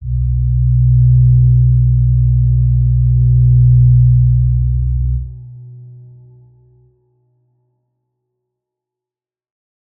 G_Crystal-B2-mf.wav